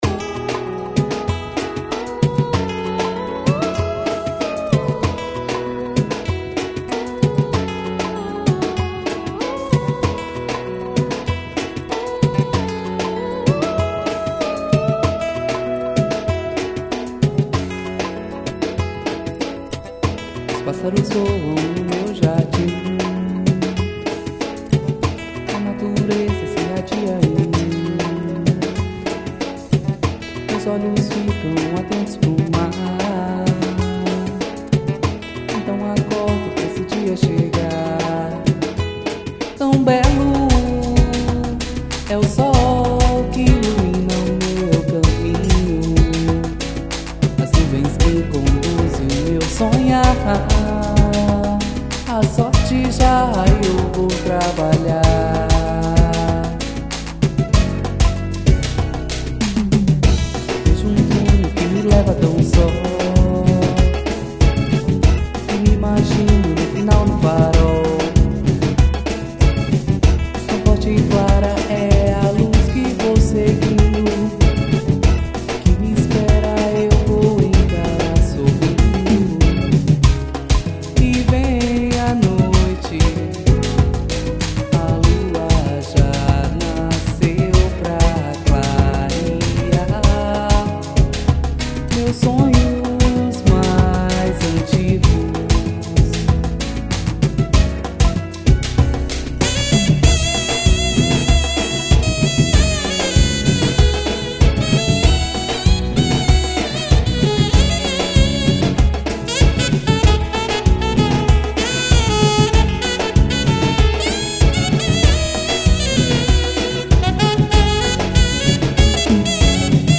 EstiloAxé